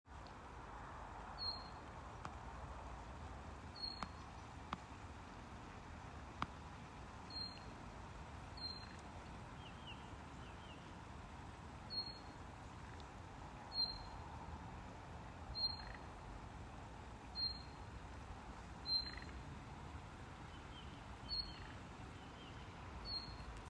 Birds -> Thrushes ->
Thrush Nightingale, Luscinia luscinia
StatusAgitated behaviour or anxiety calls from adults